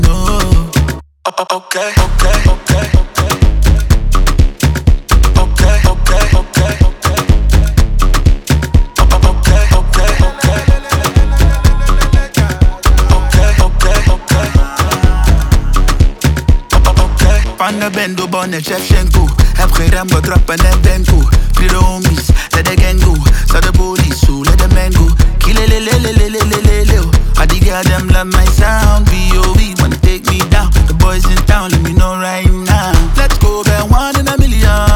Afro-Pop
2025-05-02 Жанр: Поп музыка Длительность